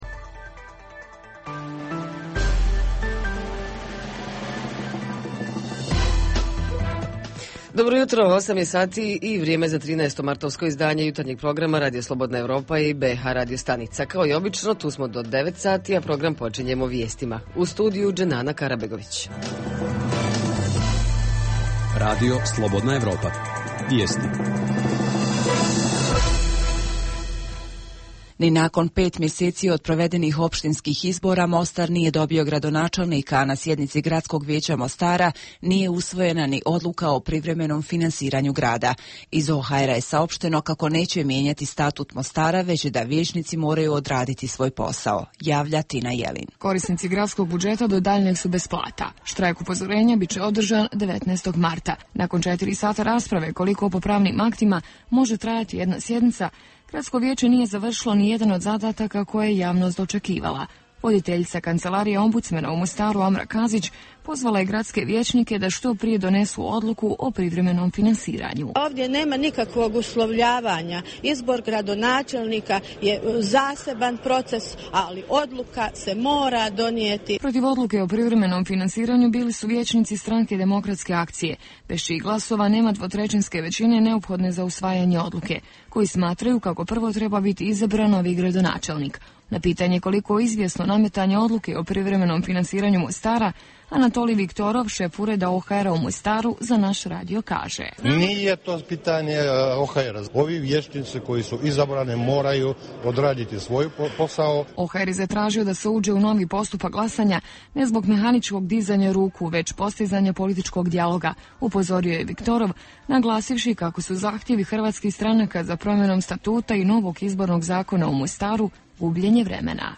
Jutarnji program za BiH koji se emituje uživo. Zajednička tema programa: uključivanje mladih u lokalnu vlast - koliko se vodi računa o tome da se što više mladih uključuje u strukture lokalnih vlasti.
Redovni sadržaji jutarnjeg programa za BiH su i vijesti i muzika.